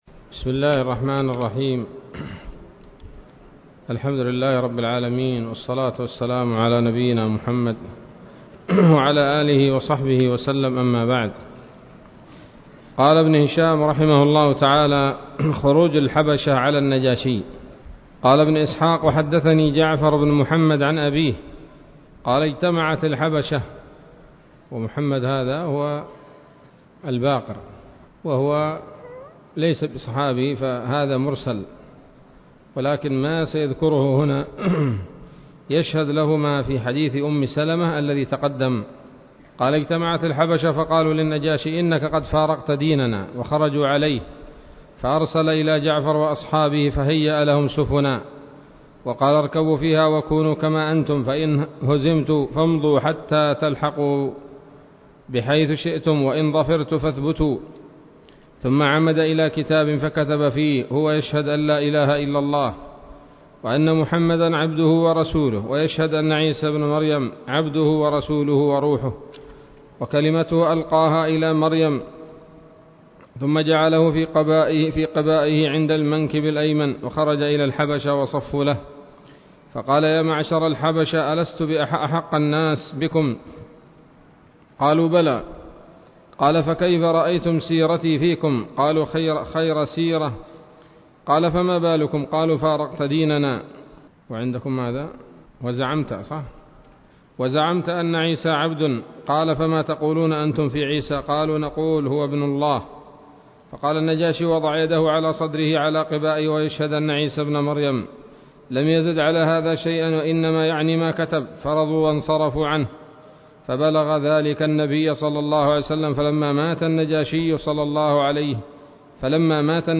الدرس السادس والثلاثون من التعليق على كتاب السيرة النبوية لابن هشام